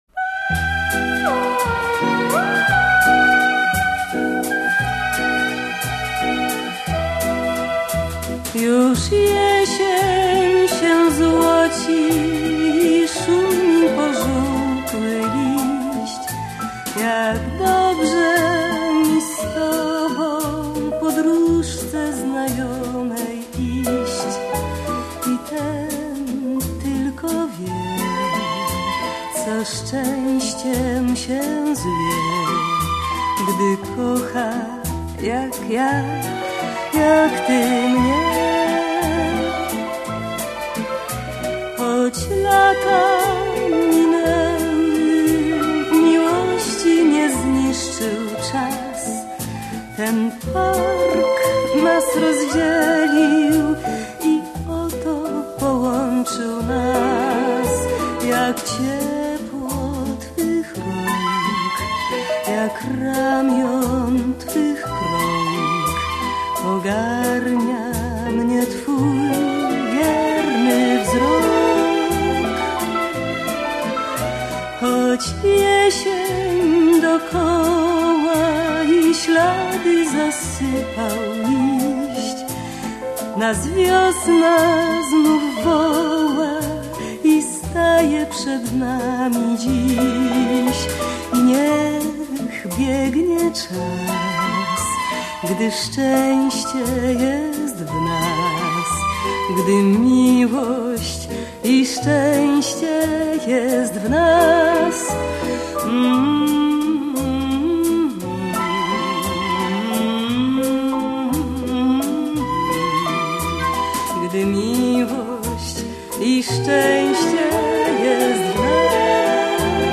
Мне очень нравится этот вальс.